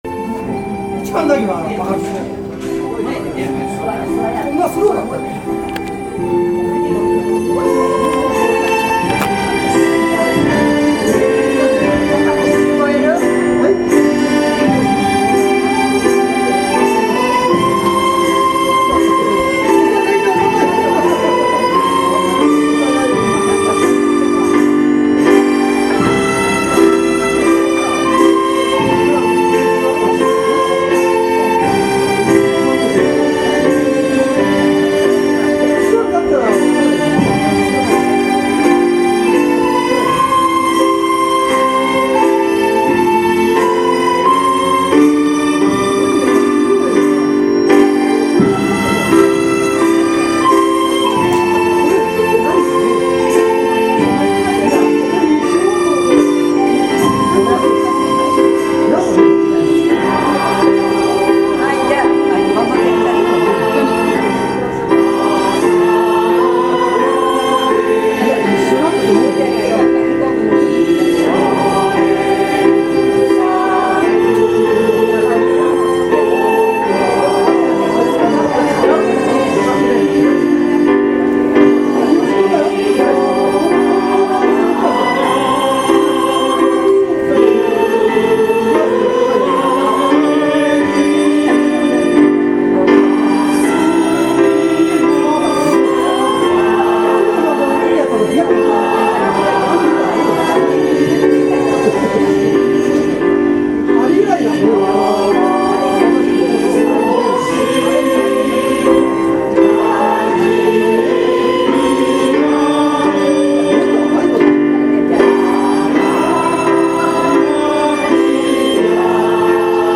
school-song-yosei.mp3